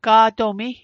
Pronunciation Guide: gaa·do·mi Translation: Eelskin